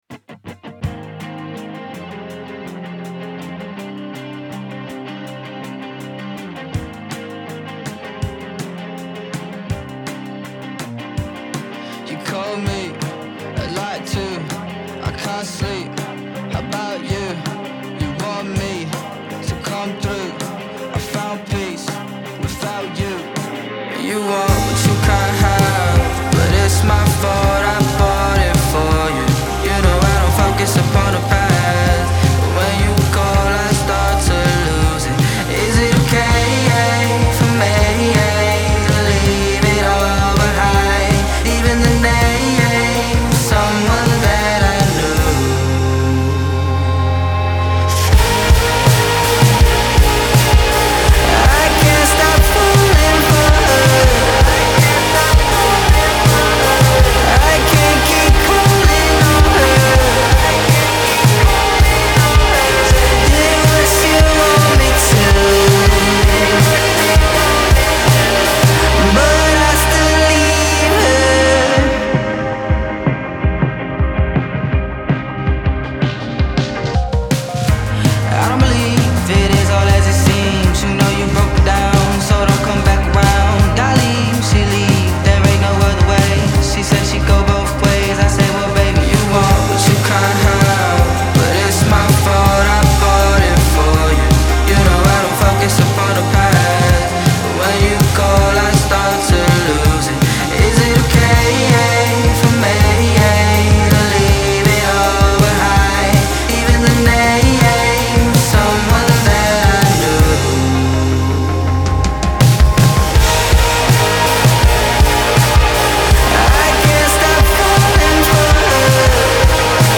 چنل موزیک New 2024 Alternative Indie